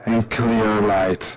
标签： 清唱 DJ DJ-工具 口语 声乐
声道立体声